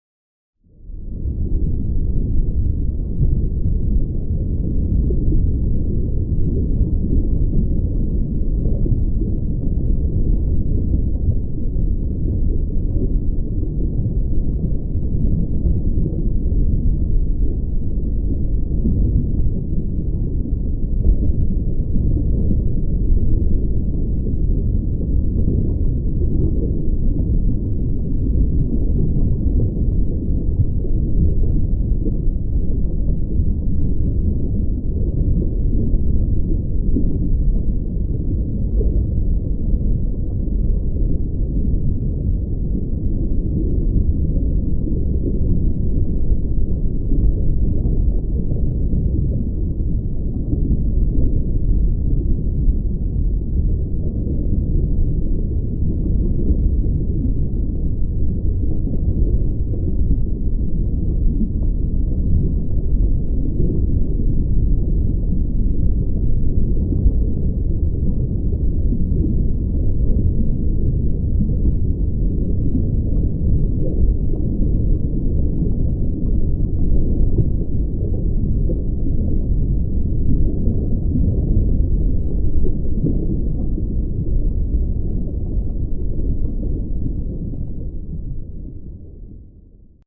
underwater.mp3